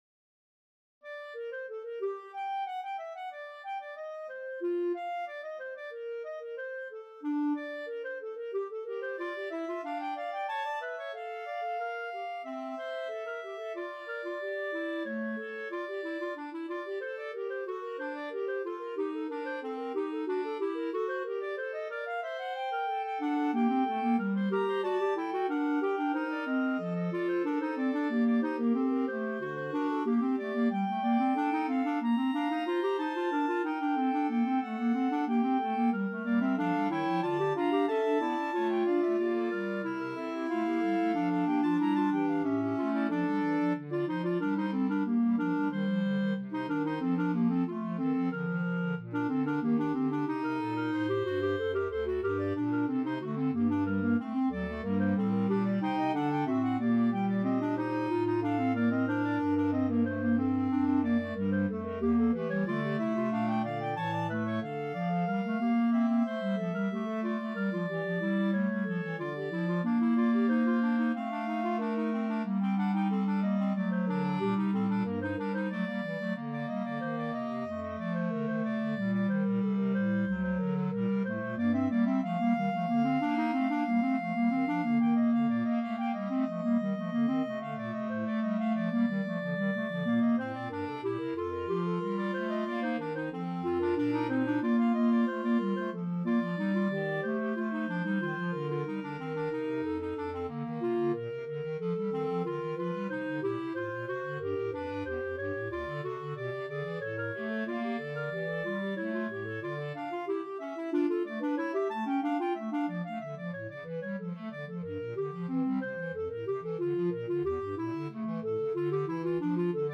Clarinet 1Clarinet 2Clarinet 3Bass Clarinet
4/4 (View more 4/4 Music)
Classical (View more Classical Clarinet Quartet Music)